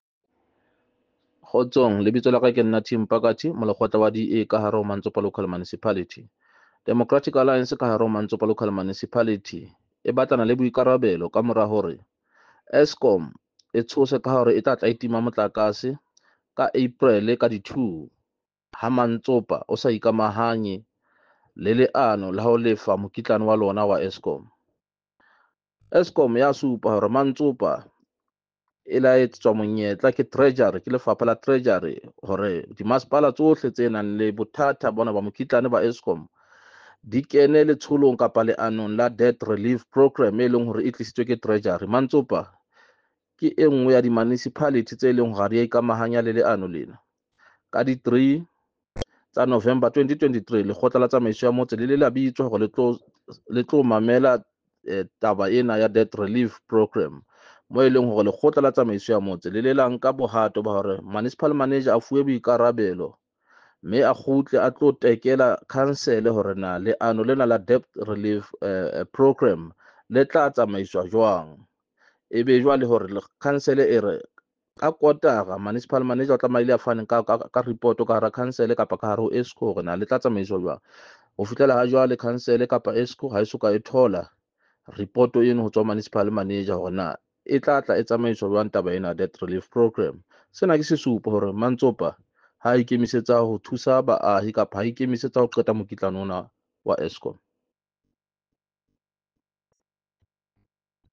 Sesotho soundbites by Cllr Tim Mpakathe.